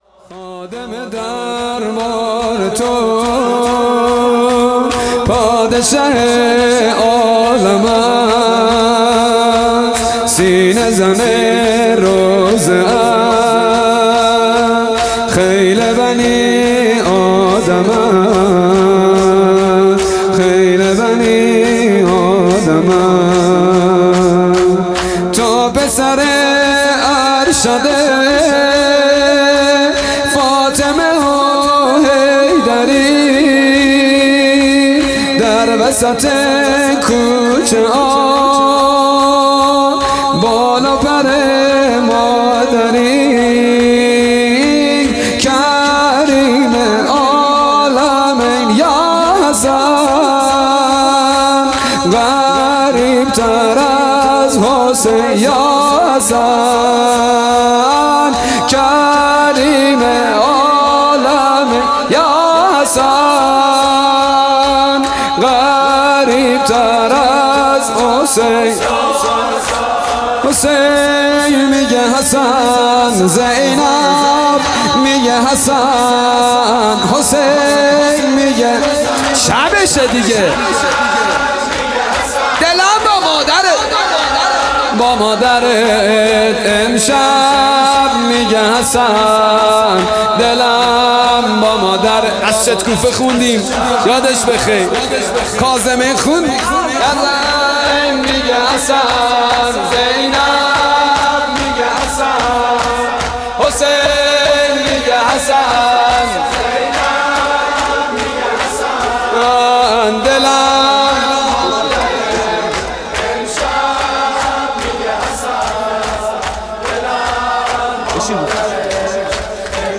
مراسم عزاداری پنج شب آخر صفر